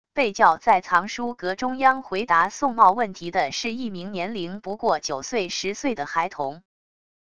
被叫在藏书阁中央回答宋茂问题的是一名年龄不过九岁十岁的孩童wav音频生成系统WAV Audio Player